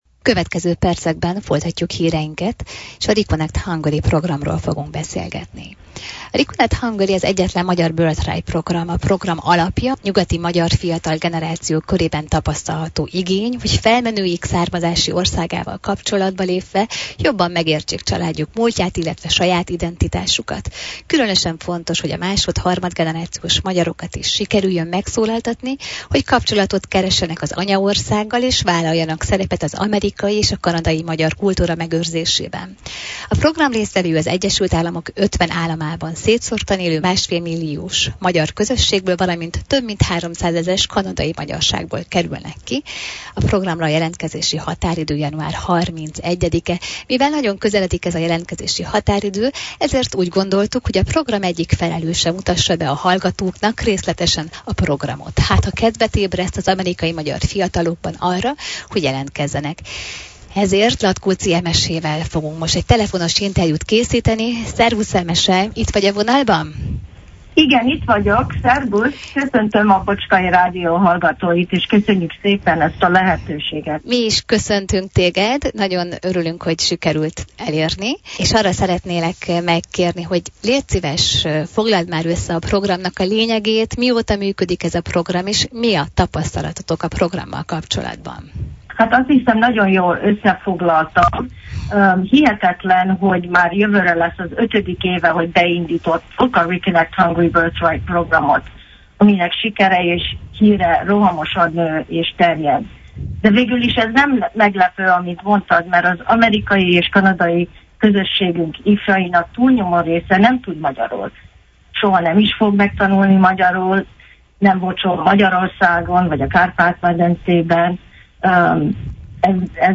kérdeztük telefonon, hogy vázolja fel a hallgatóknak a legfontosabb információkat a  programról.